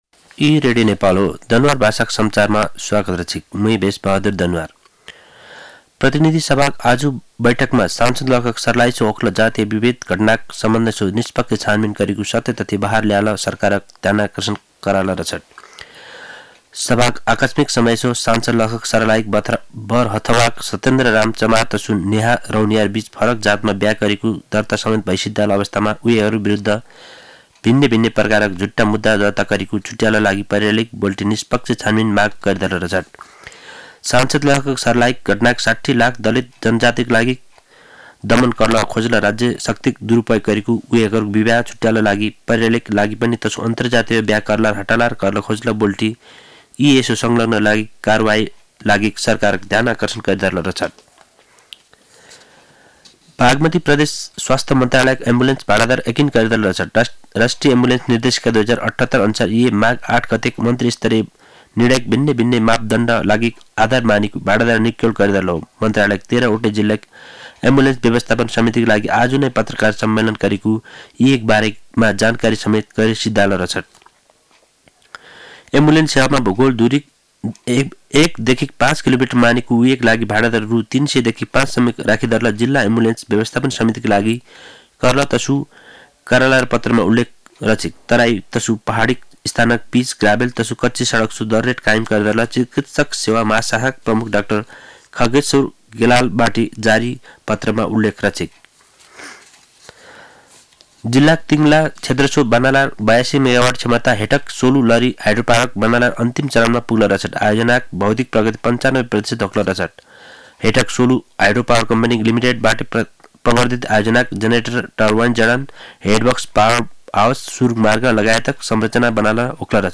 दनुवार भाषामा समाचार : २५ माघ , २०८१
danuwar-news-.mp3